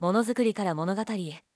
無料のAI音声合成「CoeFont Studio」がネットで話題になっていたので使ってみました。とりあえず当社の経営理念「ものづくりからものがたりへ」をAIボイスでお試し！
今回の言葉だとアリアルの方が自然な感じでした。